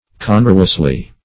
congruously - definition of congruously - synonyms, pronunciation, spelling from Free Dictionary
congruously - definition of congruously - synonyms, pronunciation, spelling from Free Dictionary Search Result for " congruously" : The Collaborative International Dictionary of English v.0.48: Congruously \Con"gru*ous*ly\, adv.
congruously.mp3